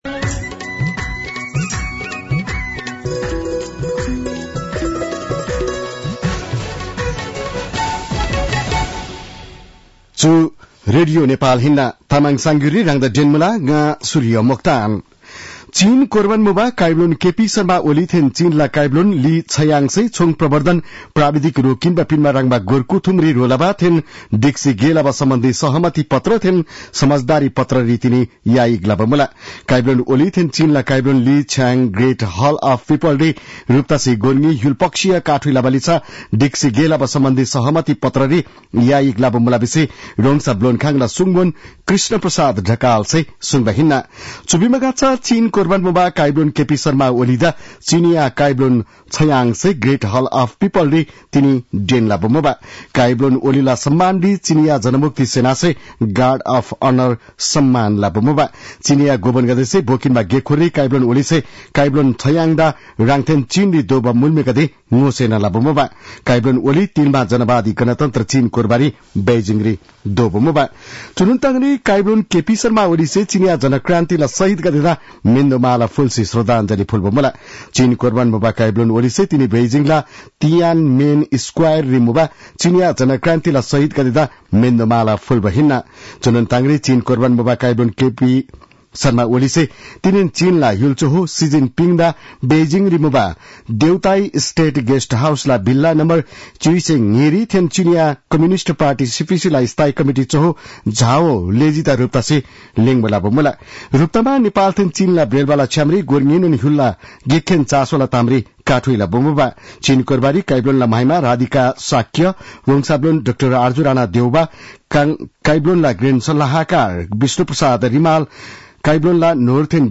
तामाङ भाषाको समाचार : १९ मंसिर , २०८१